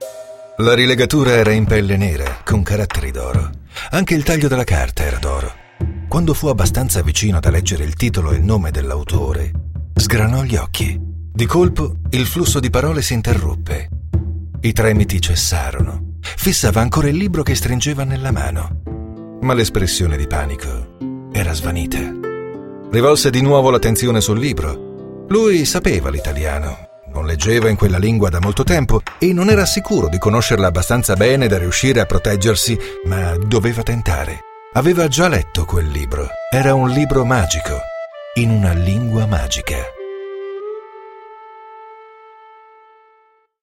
Nel mio home studio realizzo in poche ore il materiale che mi viene commissionato con qualitĂ  e precisione che vi invito a provare subito. hanno scelto la mia voce in passato: Bayer, Ryan Air, Castrol Oil, Enel, Gazzetta dello Sport, Gewiss, Foppapedretti e... molti altri
Speaker italiano madrelingua, voce calda e professionale, rassicurante e convincente.
Sprechprobe: Industrie (Muttersprache):
My voice is deep and warm, reassuring and convincing, ideal for narrations, commercials, smooth and professional for documentaries, multimedia project dvds, and many more.